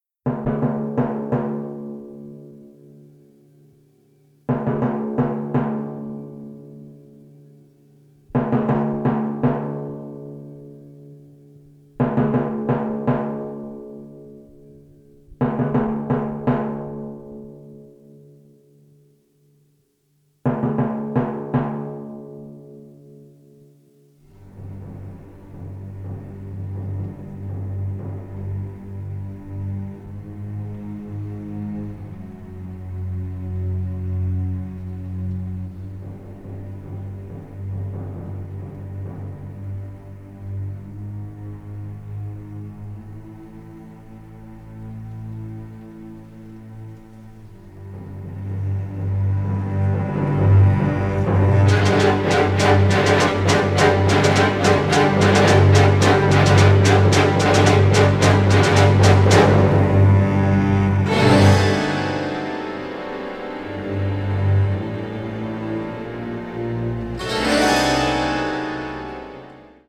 suspense writing for strings